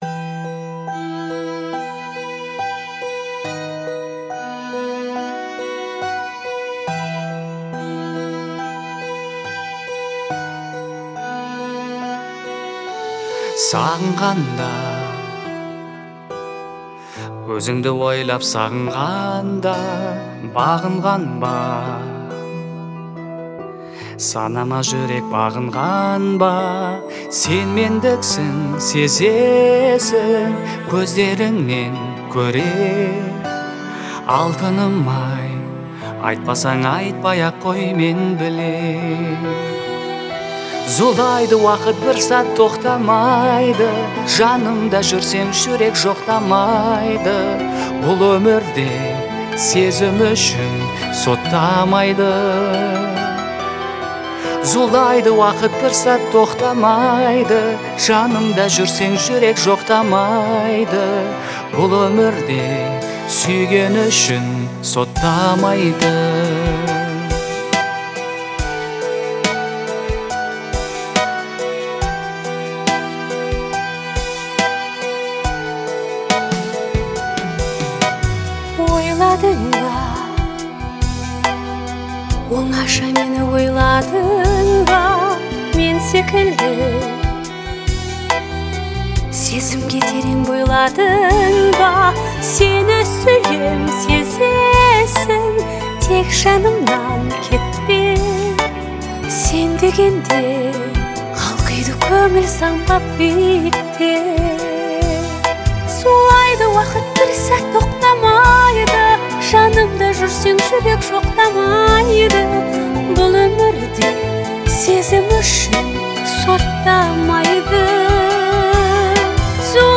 это трогательная композиция в жанре казахской поп-музыки